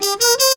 H04BRASS.wav